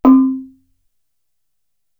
Index of /90_sSampleCDs/Best Service ProSamples vol.55 - Retro Sampler [AKAI] 1CD/Partition D/GAMELAN